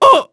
Oddy-Vox_Damage_01_kr.wav